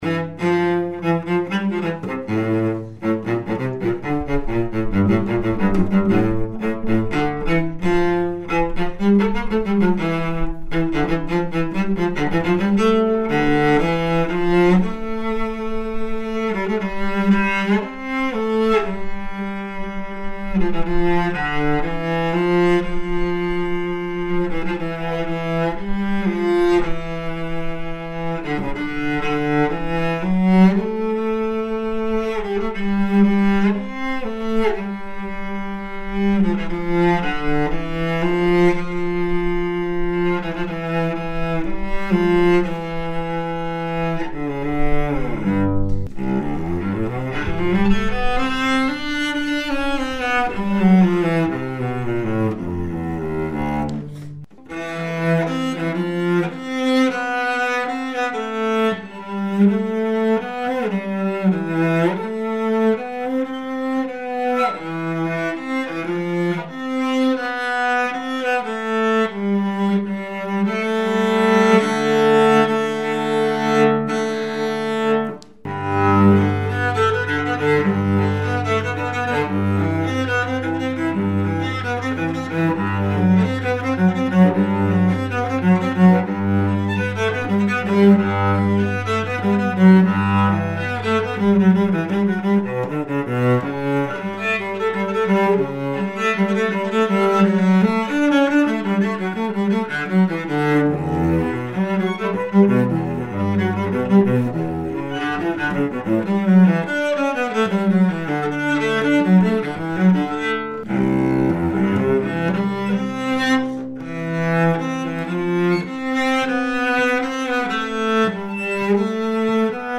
演奏者紹介)さんをお迎えし、試奏と以下のそれぞれのチェロの講評をして頂きました。